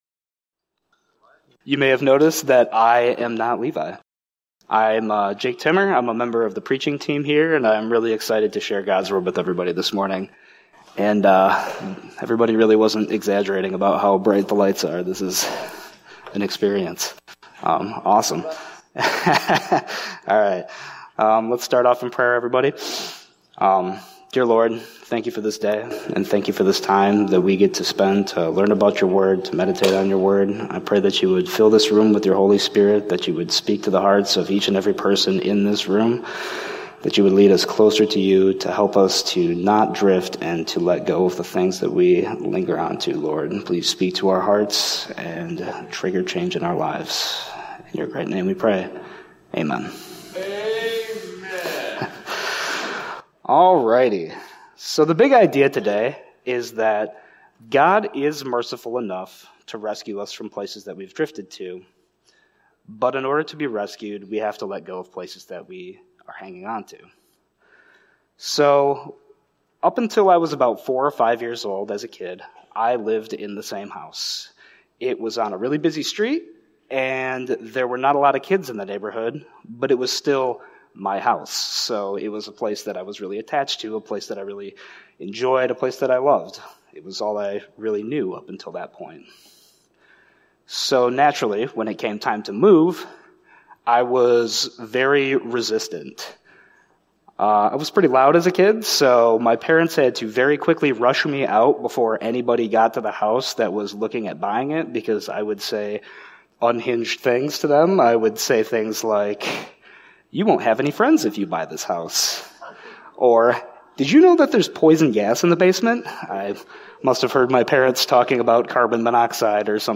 This episode of the Evangel Houghton podcast is a Sunday message from Evangel Community Church, Houghton, Michigan, February 15, 2026.